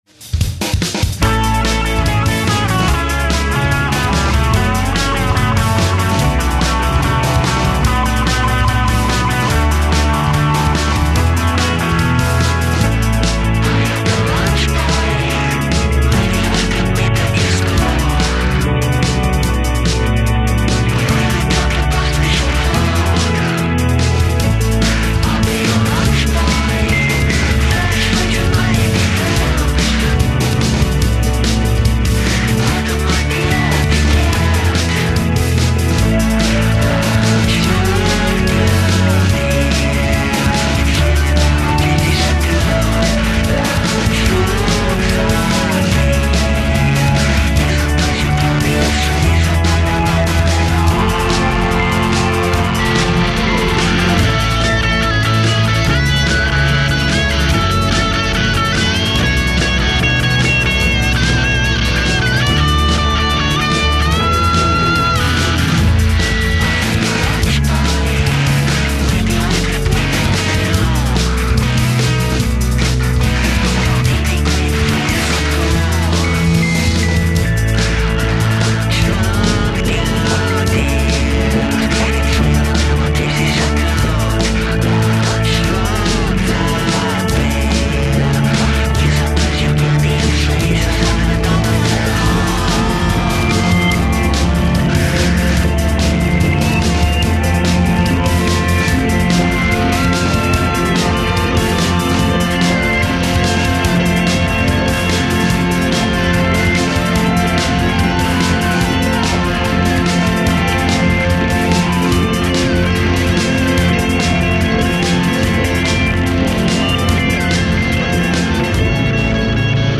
demo version